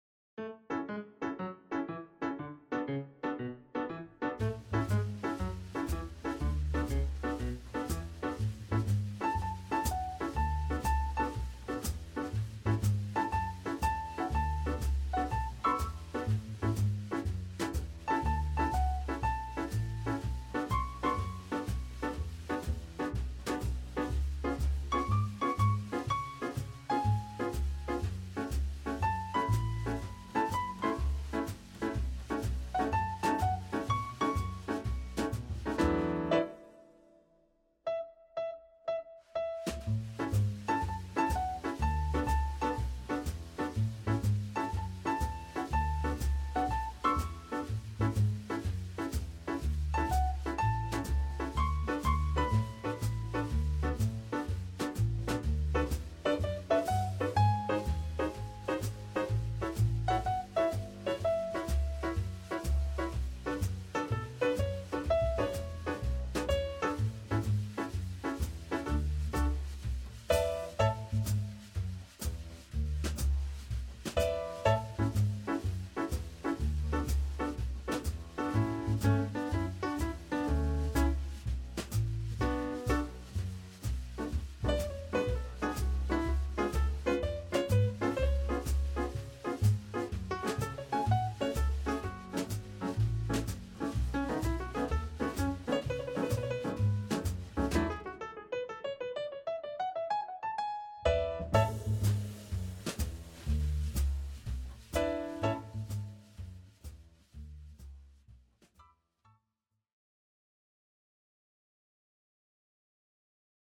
Piano, Double Bass, Drums